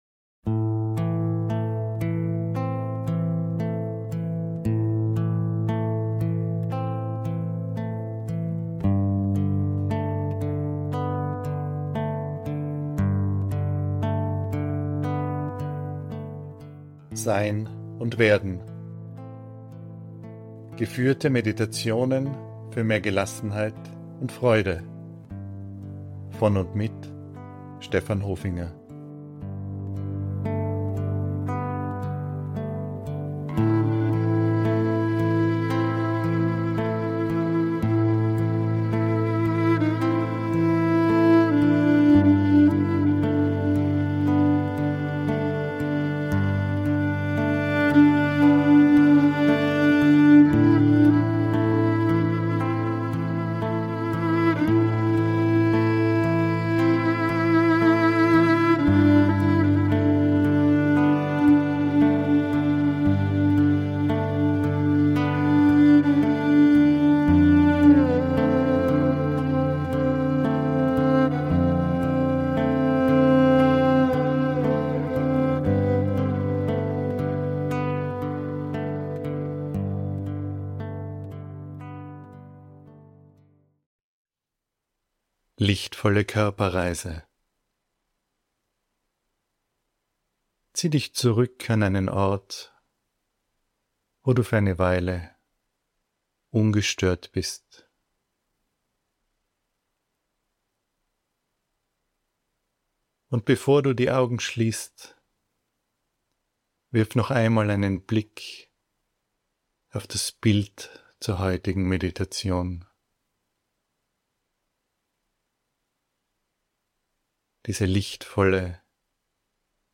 Wieder einmal eine Körperreise, diesmal in eine lichtdurchflutete Ruhe hinein. Regeneration pur.